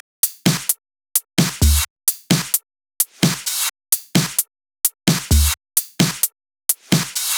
VDE 130BPM Change Drums 4.wav